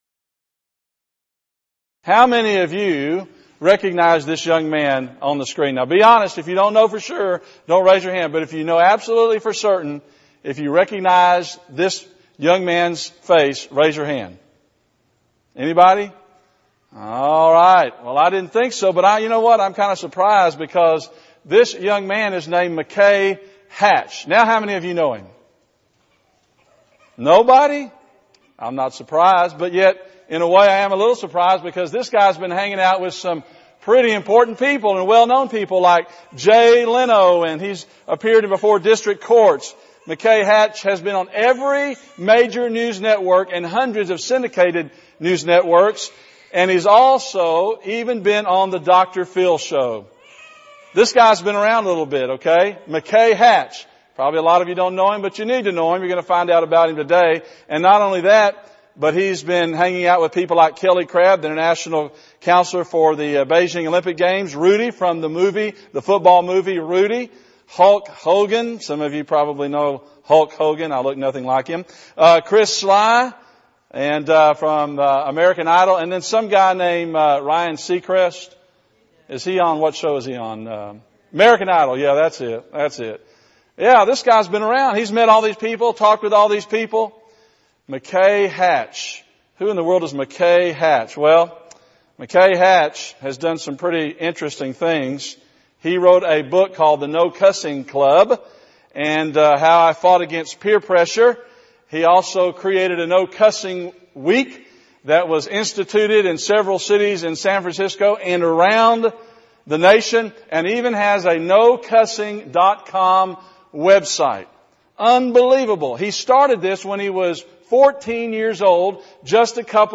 Event: 28th Annual Southwest Lectures
lecture